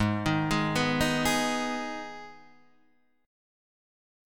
Abm chord